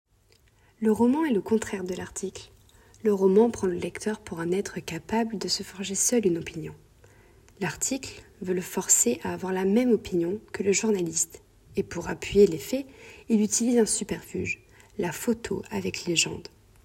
Voix off
Bandes-son
- Mezzo-soprano